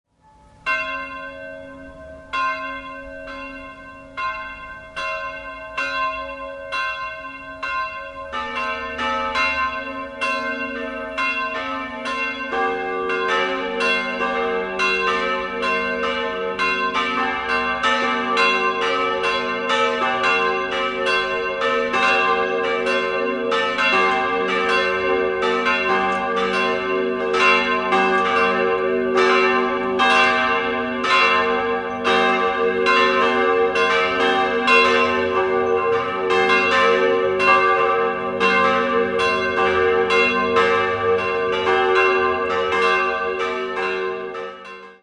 3-stimmiges Gloria-Geläute: g'-a'-c''
g' 530 kg 101 cm 1950
a' 370 kg 85 cm 1795
c'' 210 kg 73 cm 1929